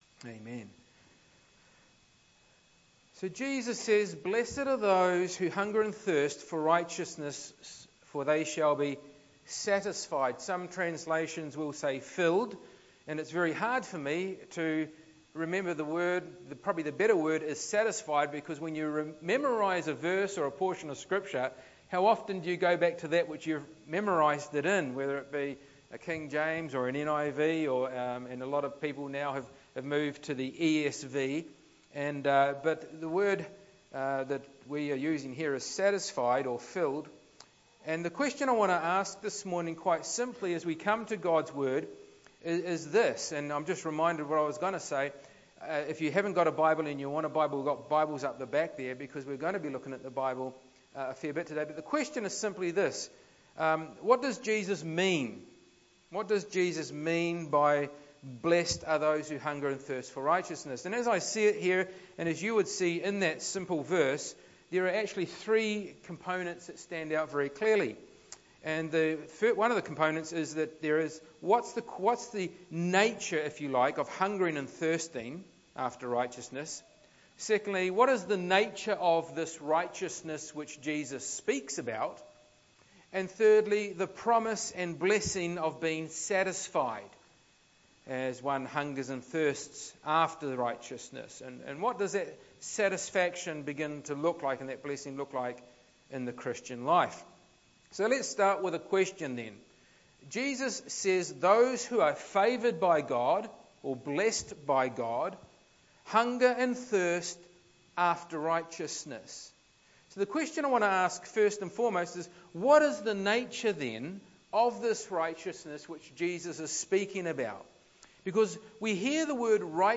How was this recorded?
Passage: Matthew 5:6 Service Type: Sunday Morning